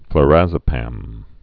(fl-răzə-păm)